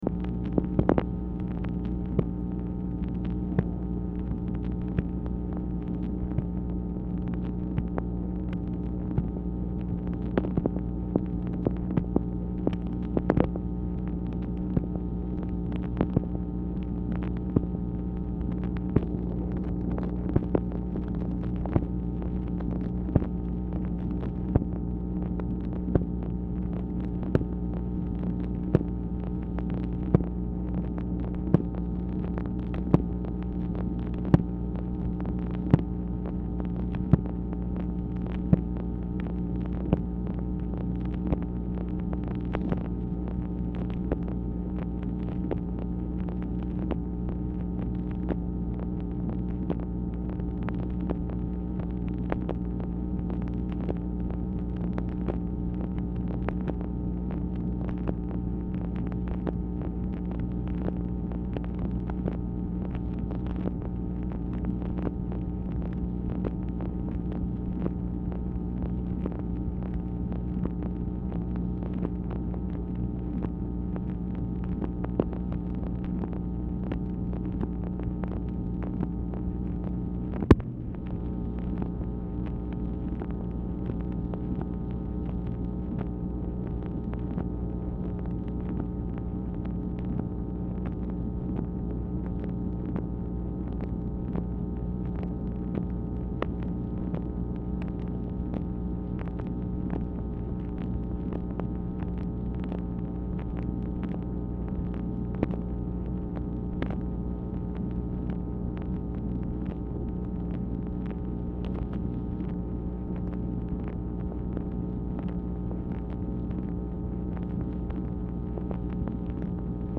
Telephone conversation # 10959, sound recording, MACHINE NOISE, 10/16/1966, time unknown | Discover LBJ
Telephone conversation
Dictation belt